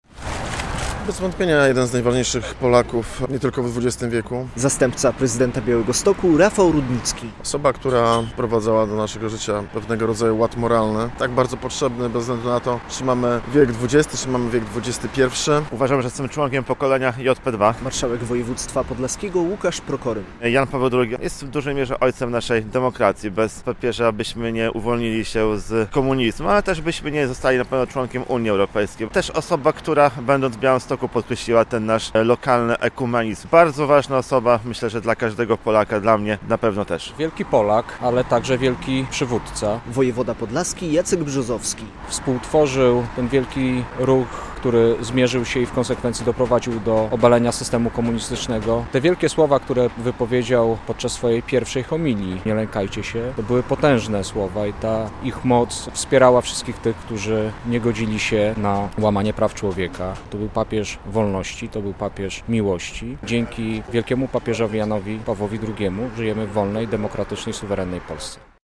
relacja
W Białymstoku samorządowcy oddali hołd św. Janowi Pawłowi II składając kwiaty przy jego pomniku przy katedrze.